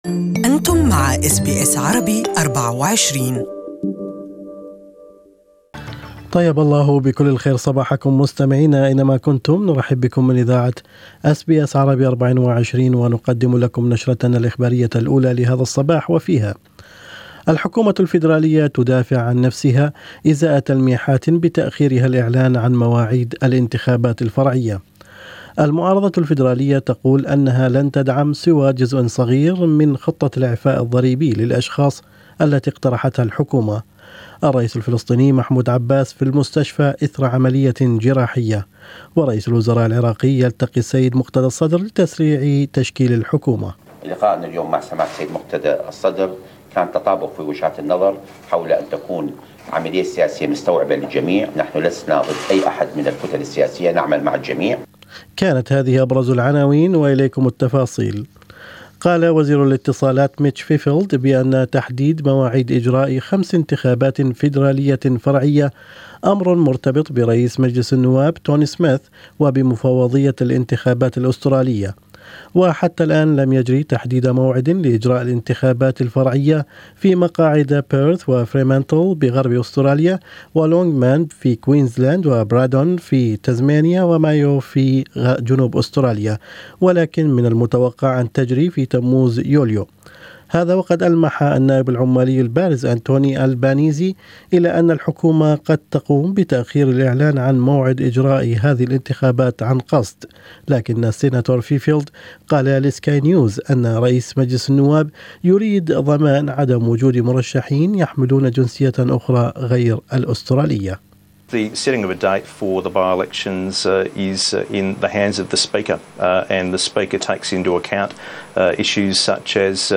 Labor frontbencher Anthony Albanese [[AL-ban-EEZ-ee]] has suggested the government is delaying the dates on purpose.